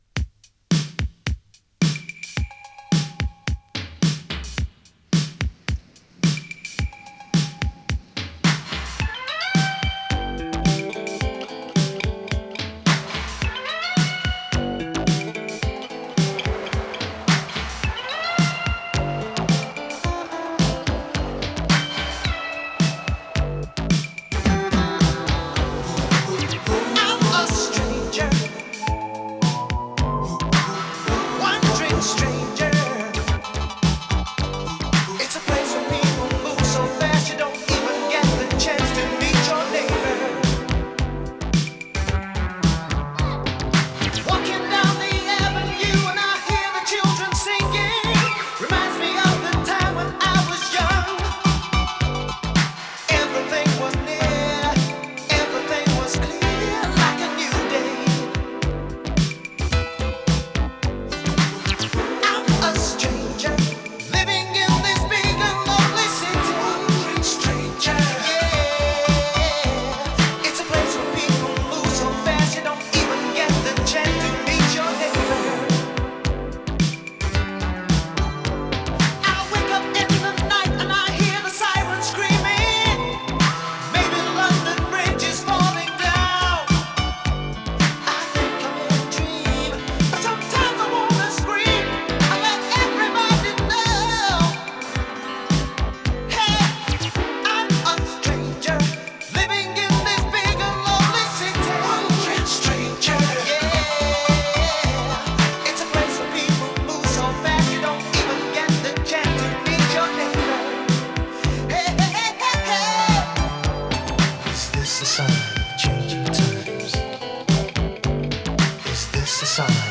胸騒ぎを掻き立てるアーバン・エレクトロ・ファンク！